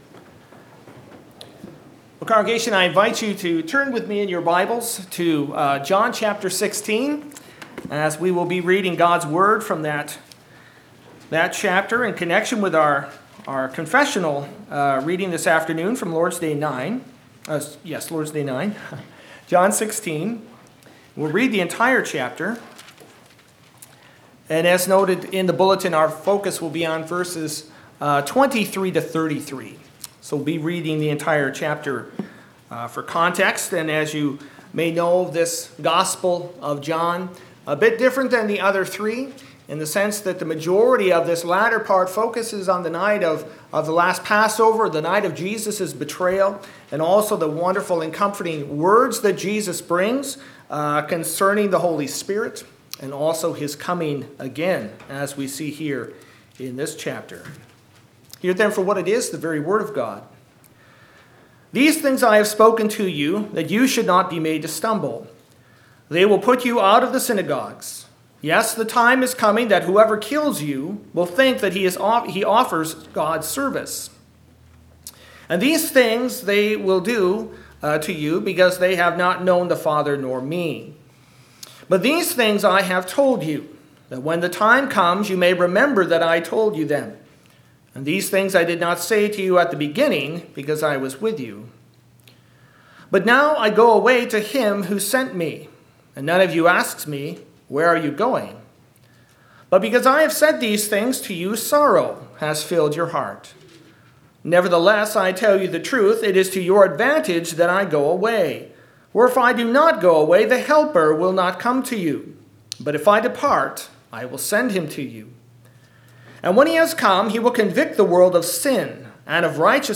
Preacher
Passage: John 16 Service Type: Sunday Afternoon